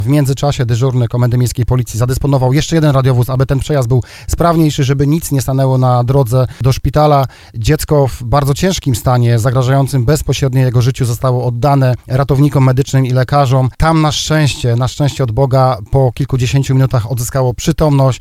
Jak mówił na antenie RDN Małopolska w programie Pomagamy i chronimy podkom.